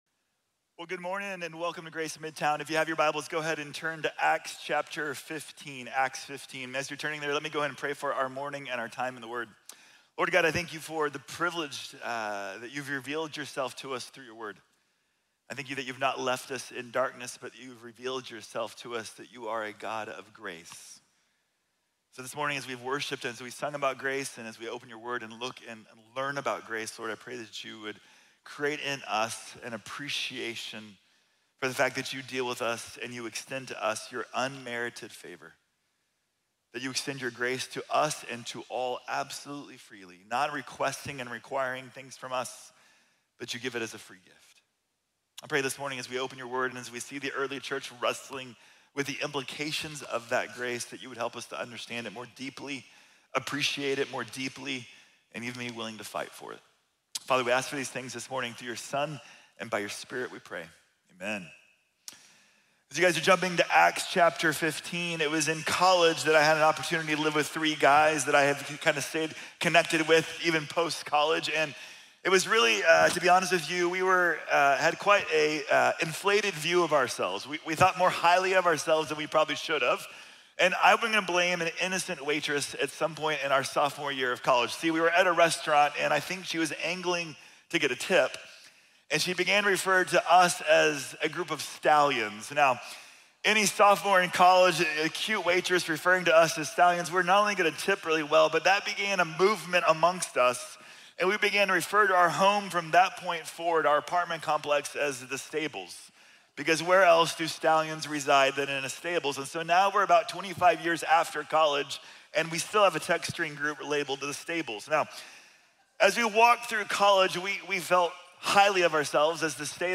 La Invitación de la Gracia | Sermón | Iglesia Bíblica de la Gracia